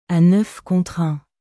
À neuf contre unア ヌァフ コーント ルァン